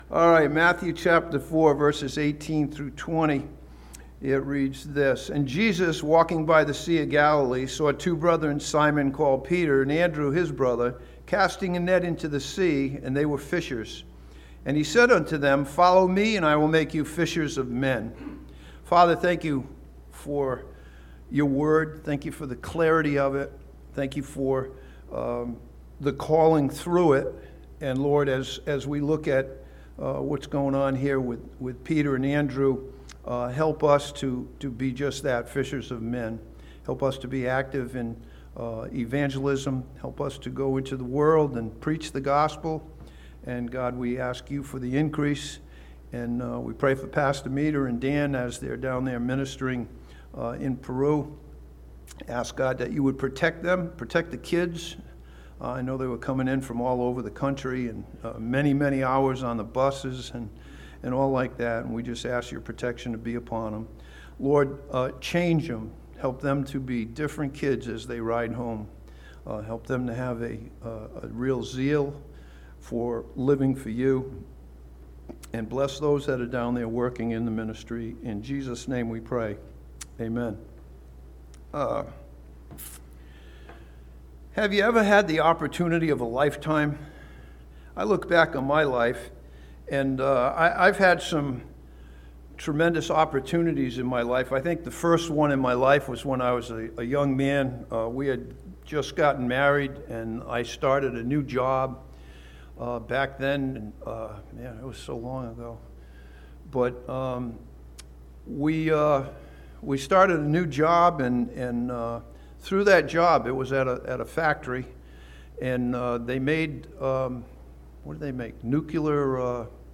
This sermon from Matthew chapter 4 challenges us as believers to keep serving the Lord.